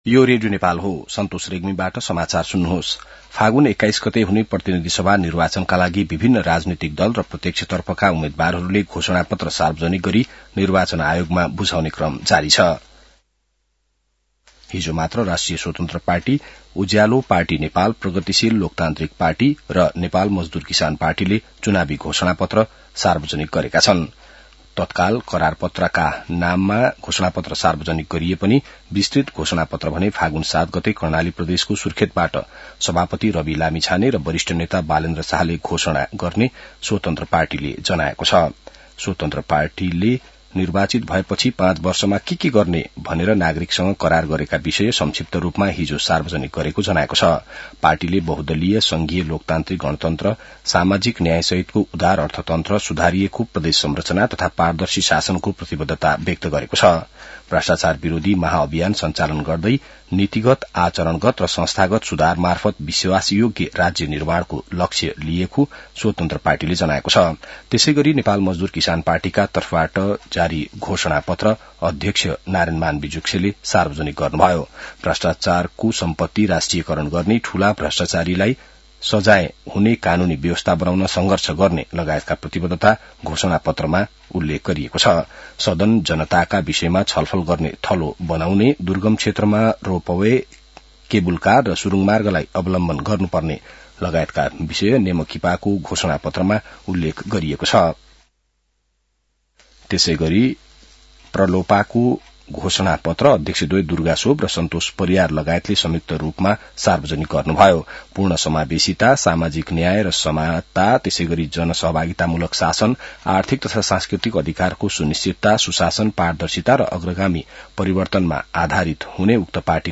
बिहान ६ बजेको नेपाली समाचार : ४ फागुन , २०८२